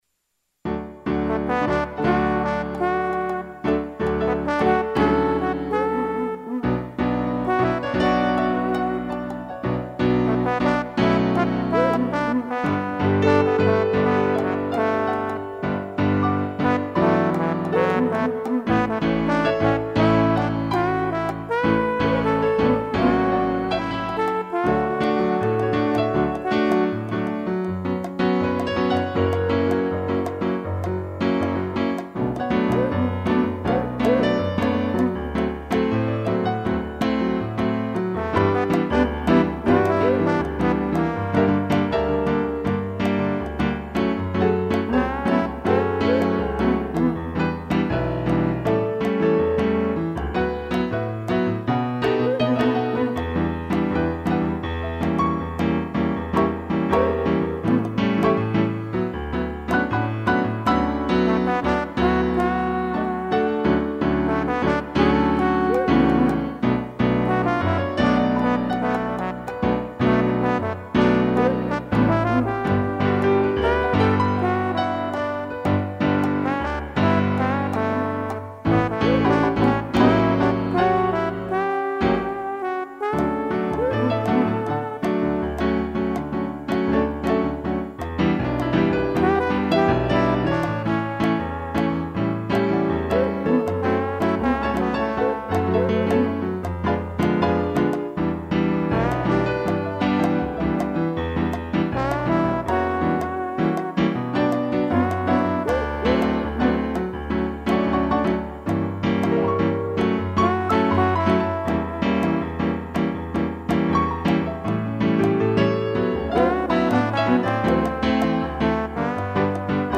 2 pianos, trombone e tamborim
(instrumental)